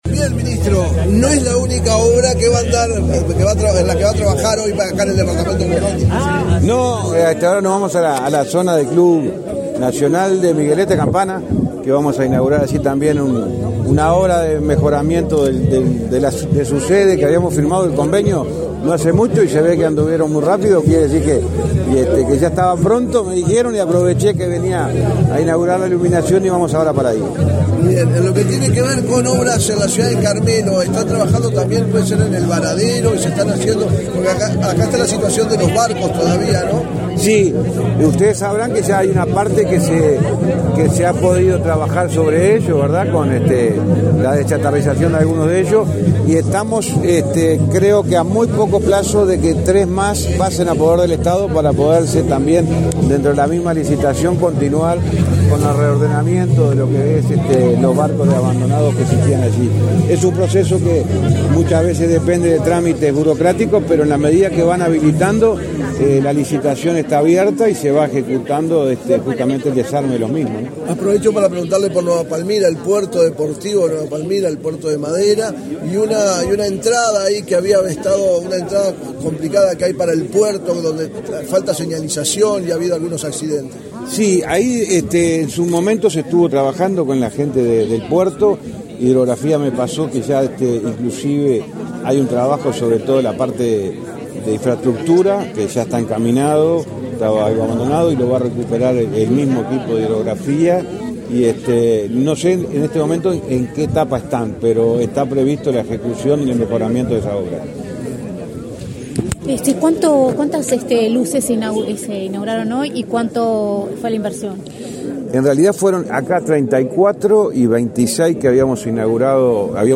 Declaraciones a la prensa del ministro de Transporte, José Luis Falero
Tras participar en la inauguración de obras en la localidad de Carmelo, el 14 de abril, el ministro Falero realizó declaraciones a la prensa.